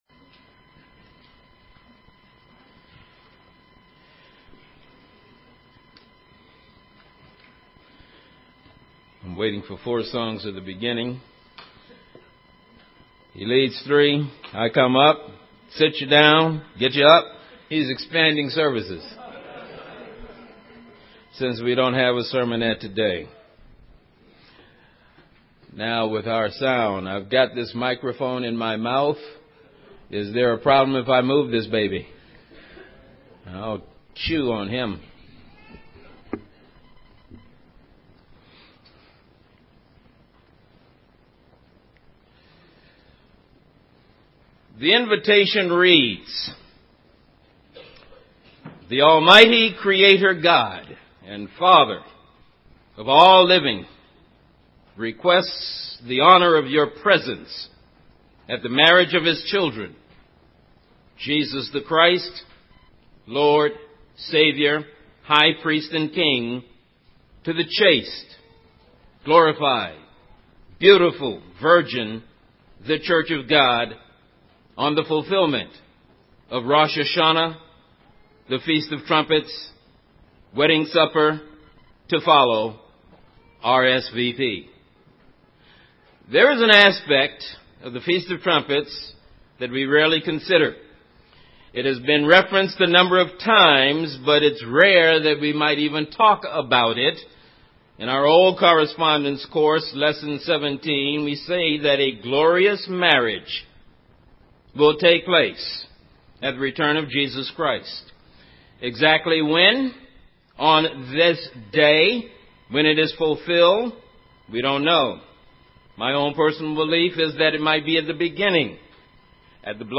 9-29-11 P.M. sermon.mp3